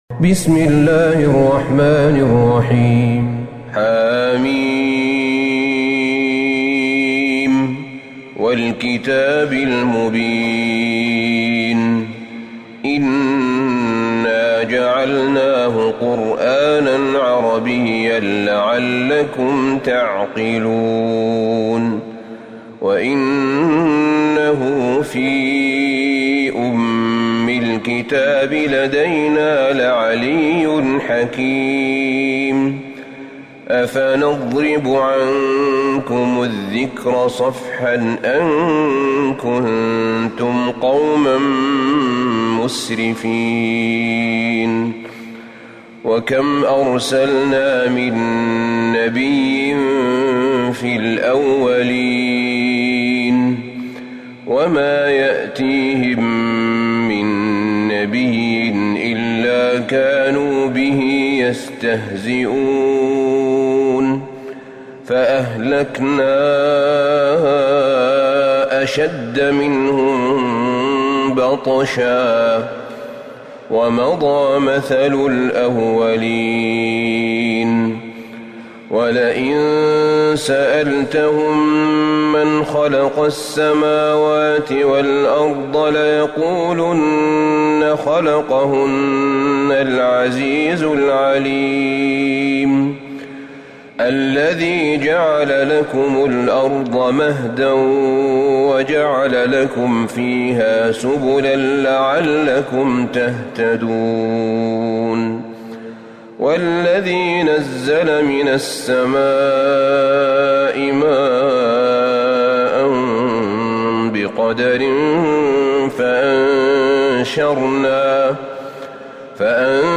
سورة الزخرف Surat AzZukhruf > مصحف الشيخ أحمد بن طالب بن حميد من الحرم النبوي > المصحف - تلاوات الحرمين